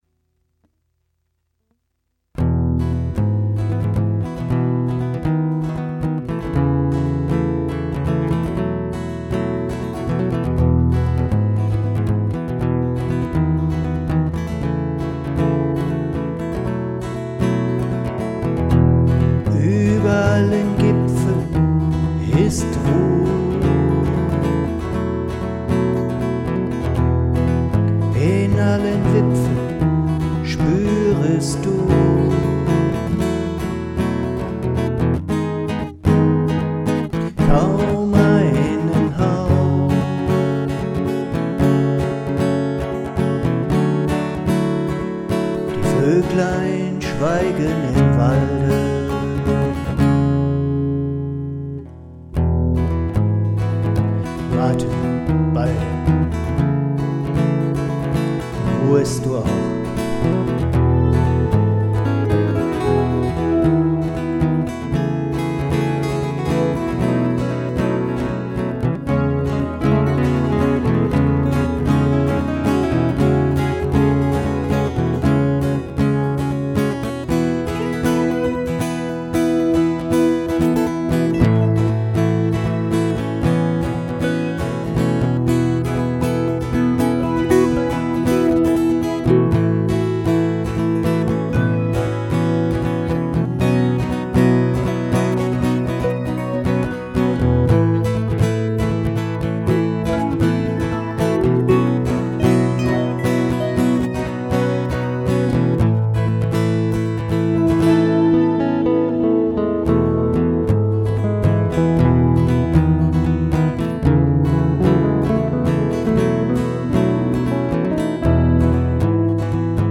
Gitarre/Voice